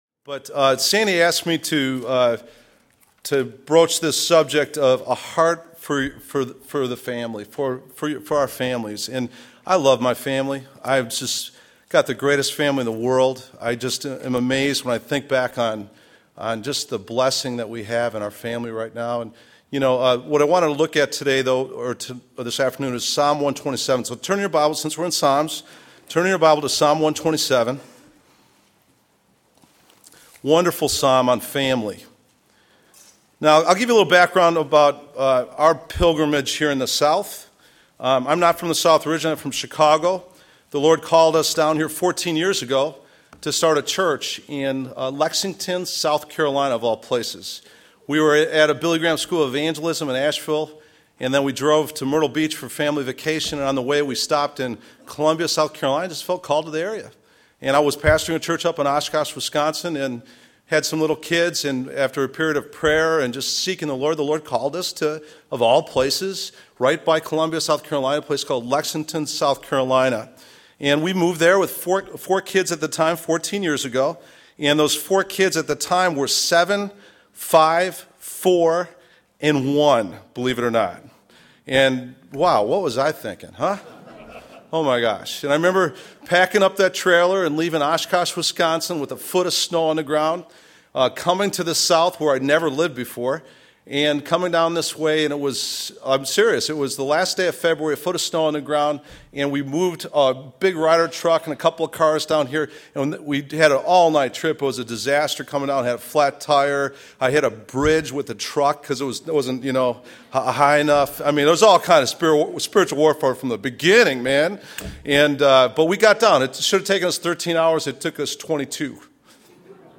2011 Home » Sermons » Session 8 Share Facebook Twitter LinkedIn Email Topics